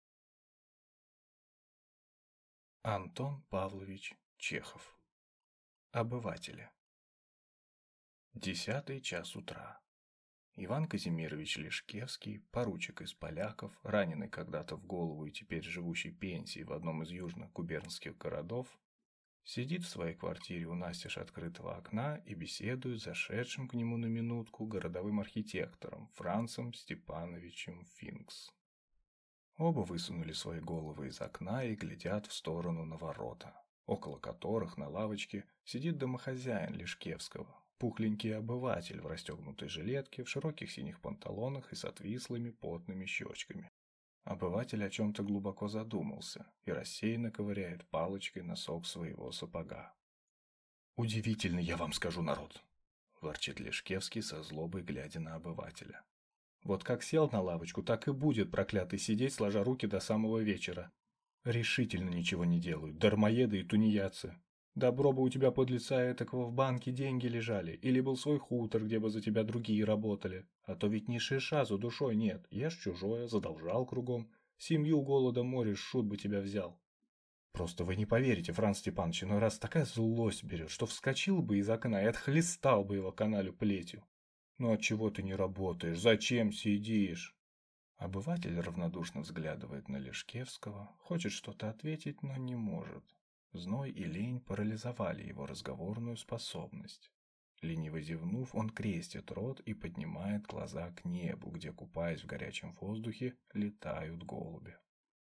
Аудиокнига Обыватели